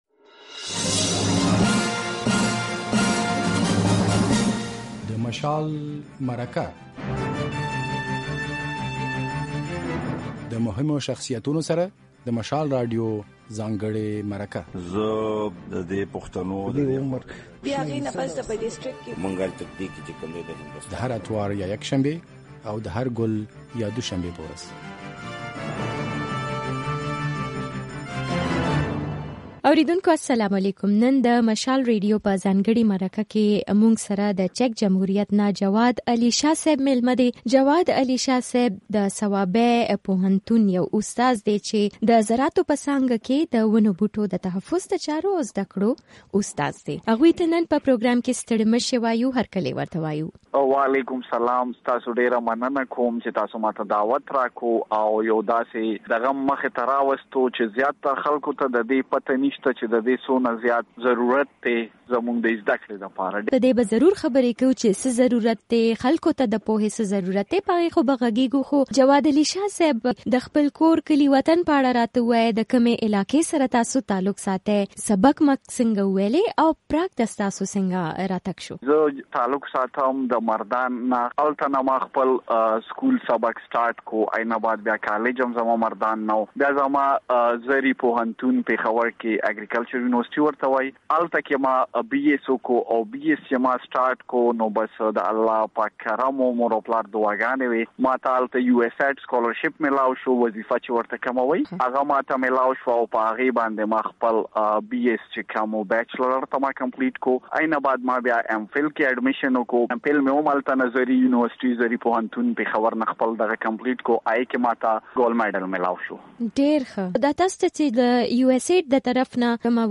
د مشال مرکه دلته واورئ